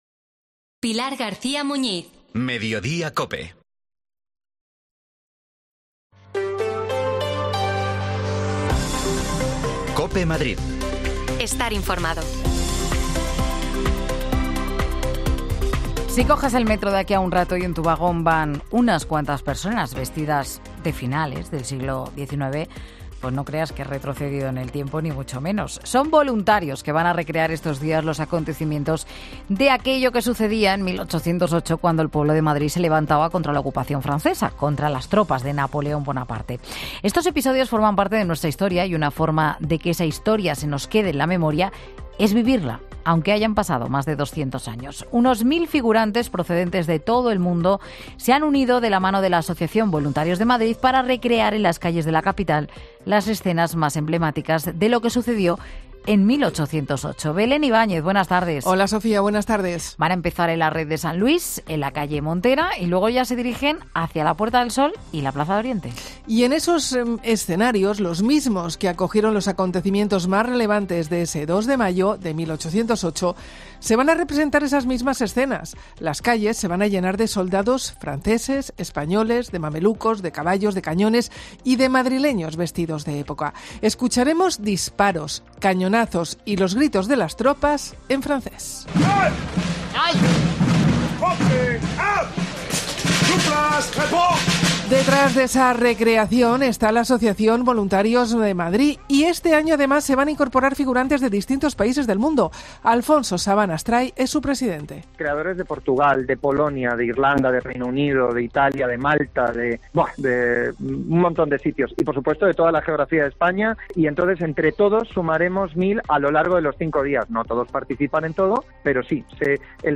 Los Voluntarios de Madrid explican las recreaciones del 2 de mayo